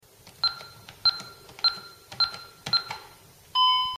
Honeywell Keypad
• Play the file below to hear the confirmation beep I'm talking about.
If you don't hear the confirmation beep, your code is probably wrong.
Honeywell-Keypad-sound.mp3